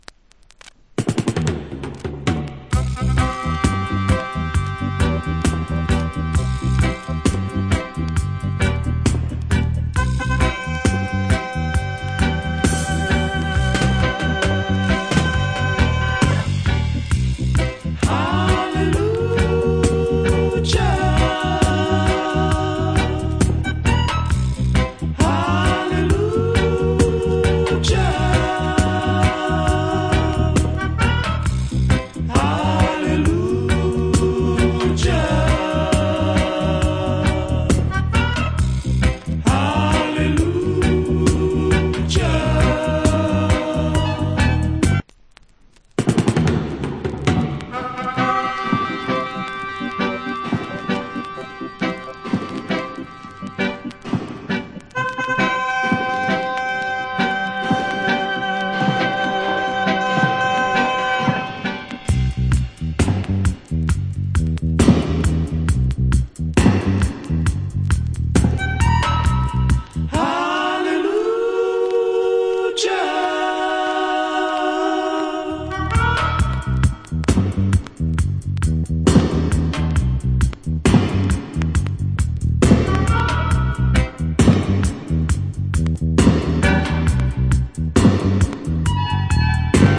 Good Roots Rock Vocal.